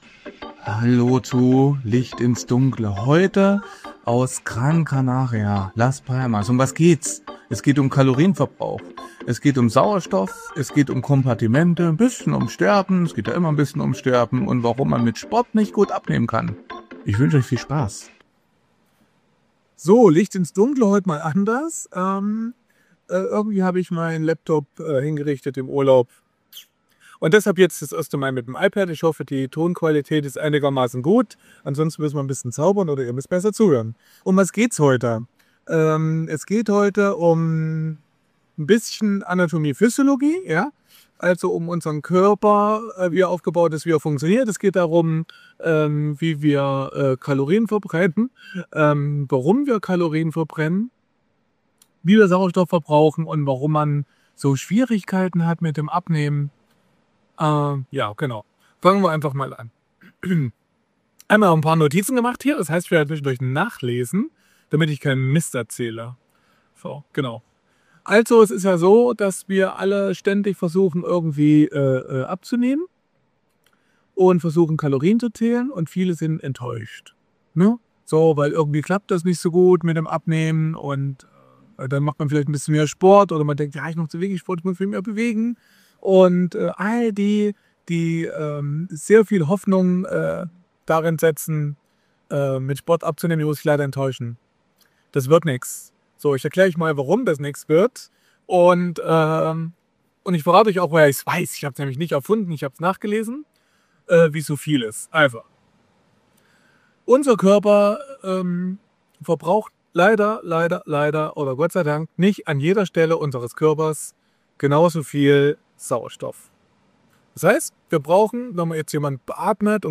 Es geht darum, wie man effektiv trainieren kann und welche Rolle die Wissenschaft bei der Gewichtsabnahme spielt. Ein Gespräch, das Missverständnisse rund um Sport und Gewichtsreduktion aufklärt und neue Perspektiven bietet.